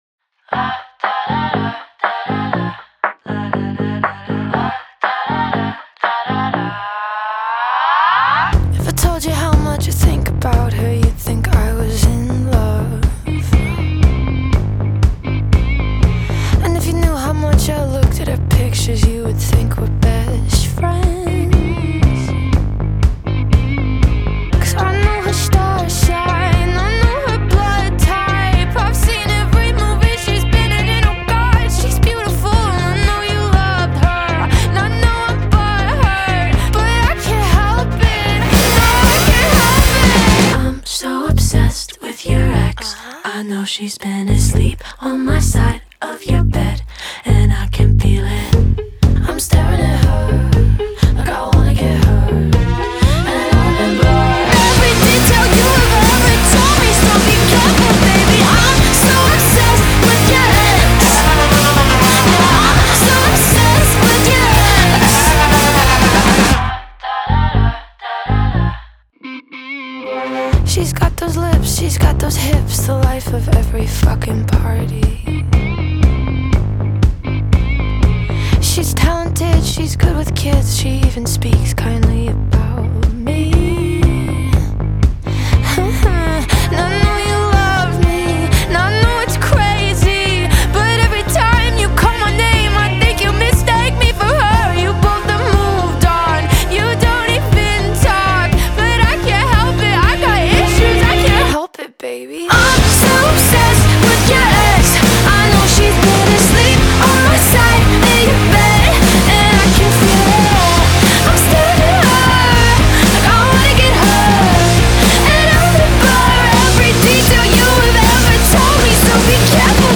BPM120-120
Audio QualityPerfect (High Quality)
Pop Rock song for StepMania, ITGmania, Project Outfox
Full Length Song (not arcade length cut)